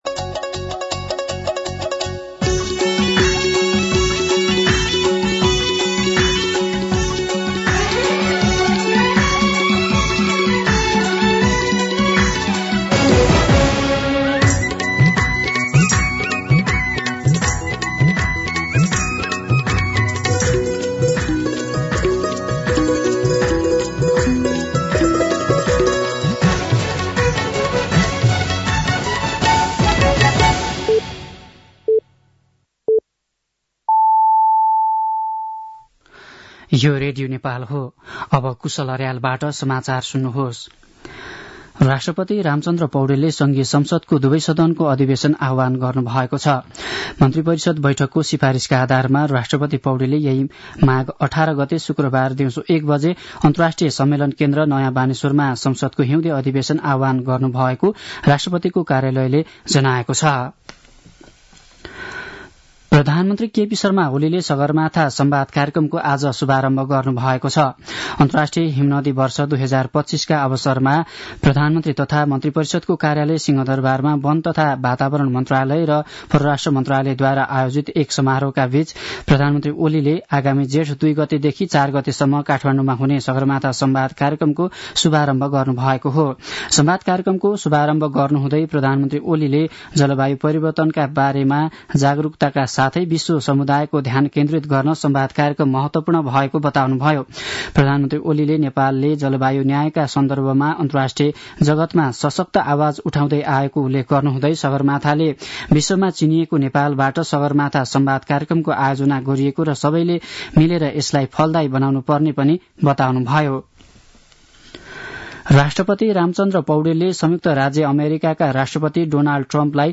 दिउँसो ४ बजेको नेपाली समाचार : ९ माघ , २०८१
4pm-News-10-8.mp3